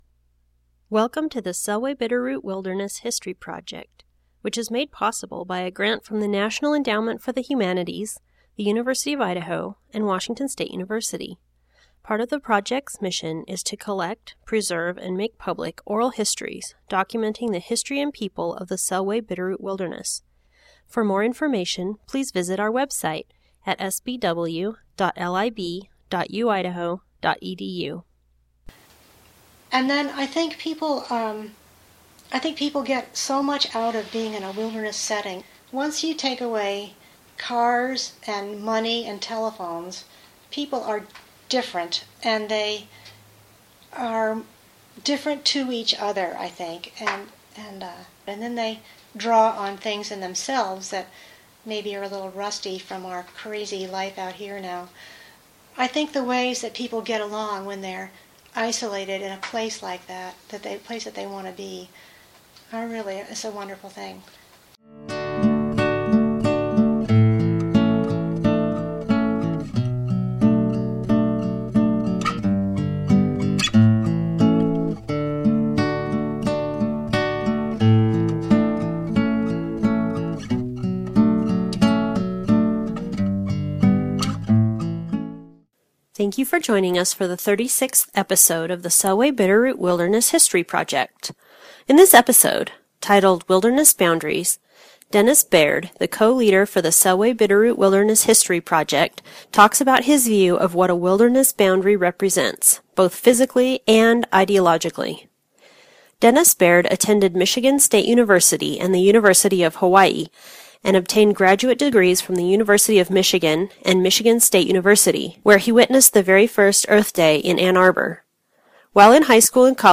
Interviewer
Location: Moscow, Idaho